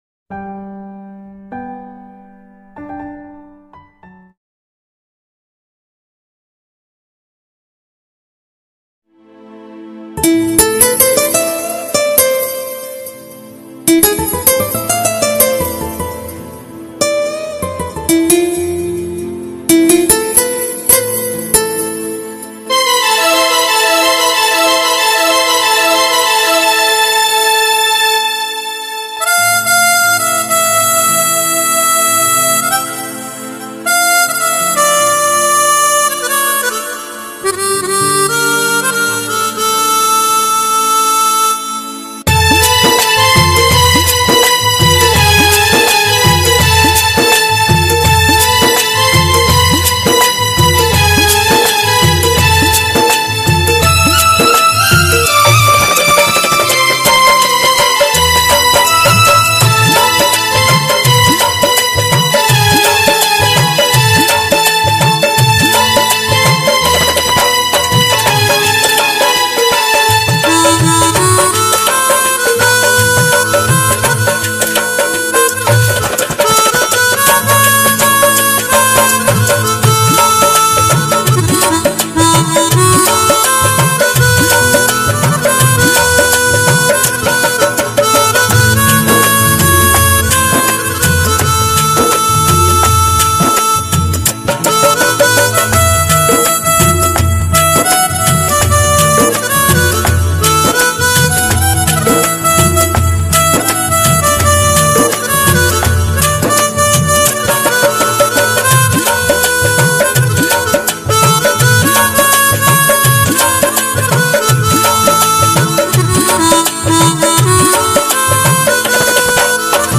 Keyboard
Instrumental Music And Rhythm Track